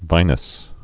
(vīnəs)